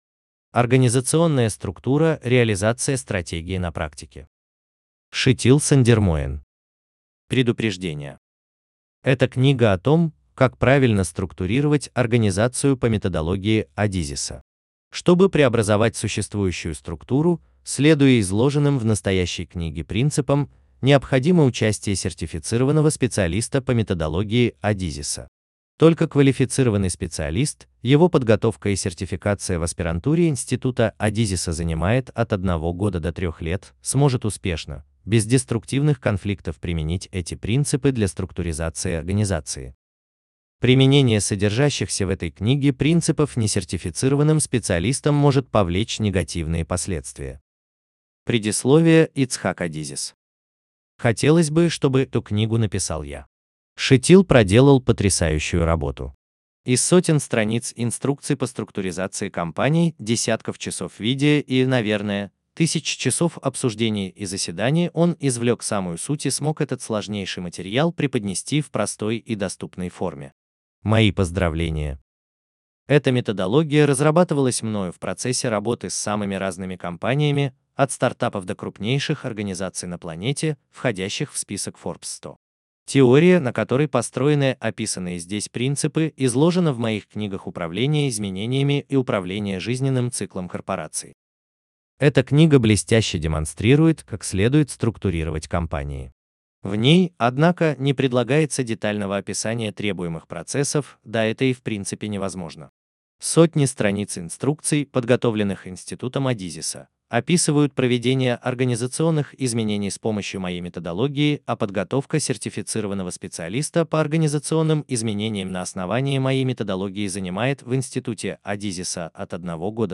Аудиокнига Организационная структура | Библиотека аудиокниг